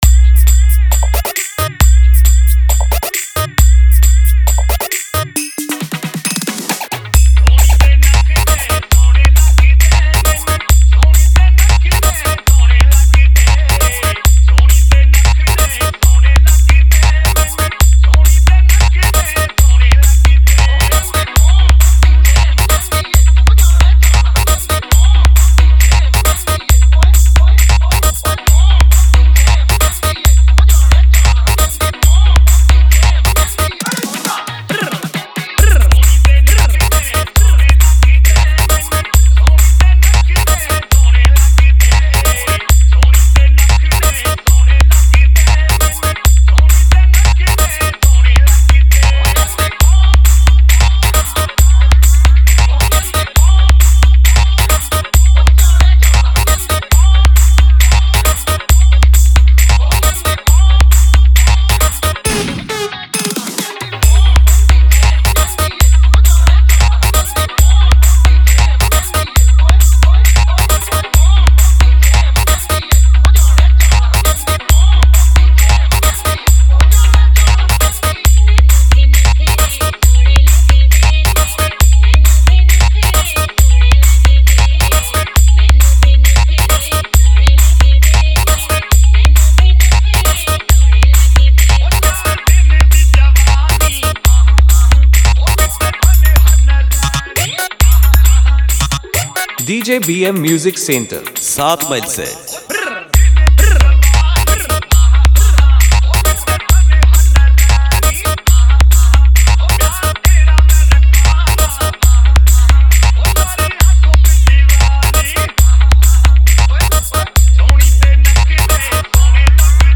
Spl Drop Humming Bass Piano Mix